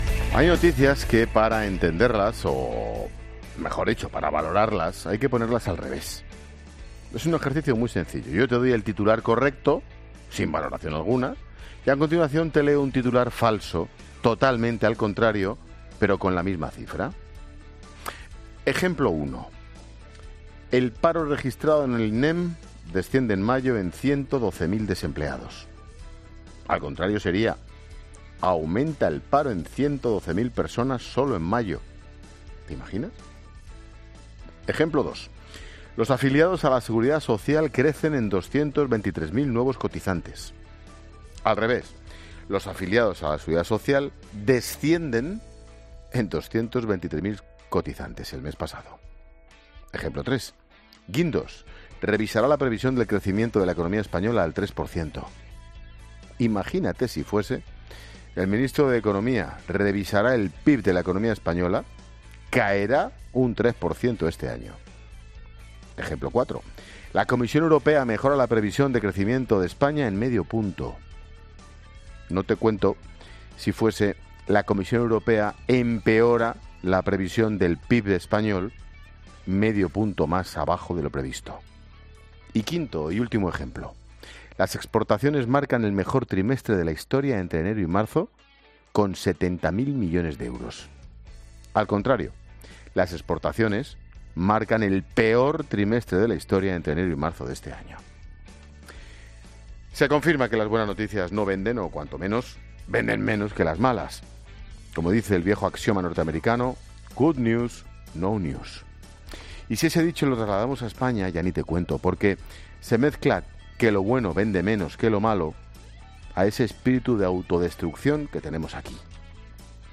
Monólogo de Ángel Expósito a las 16h. analizando los últimos datos del paro.